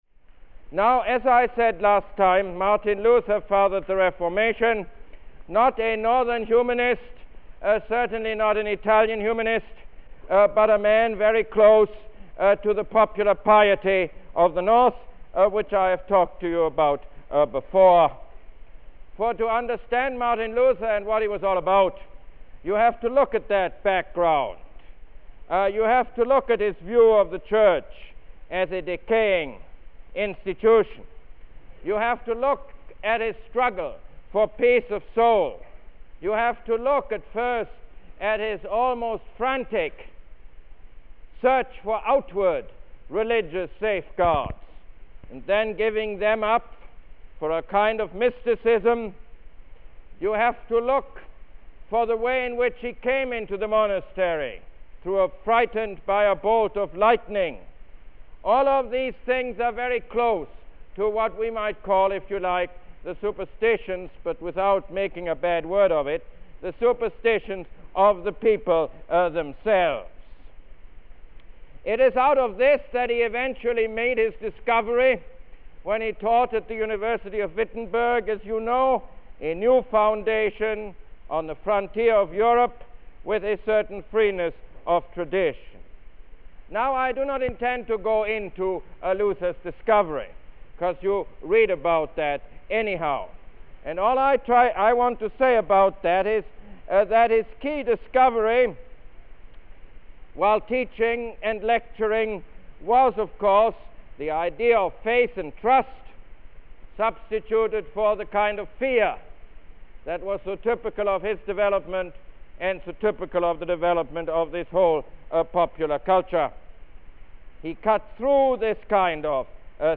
Lecture #5 - Martin Luther